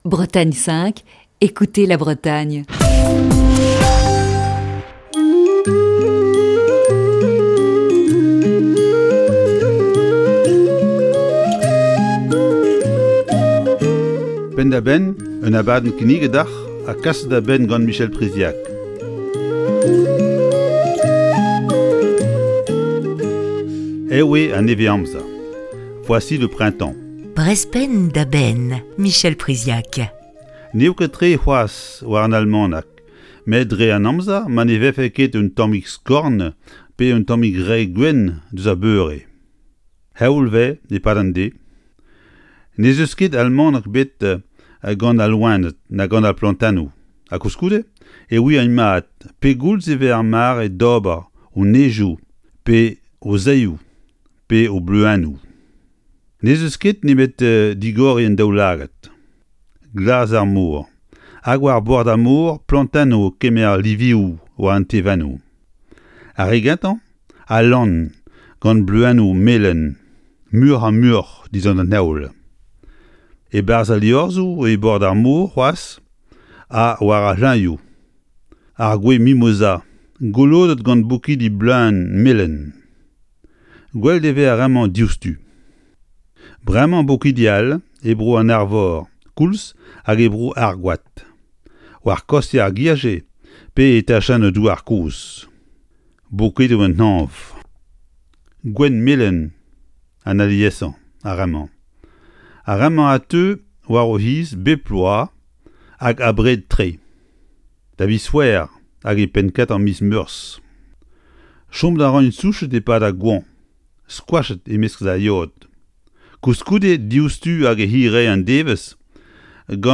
Chronique du 8 mars 2021.